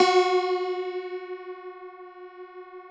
53q-pno12-F2.aif